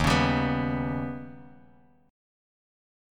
D#+7 chord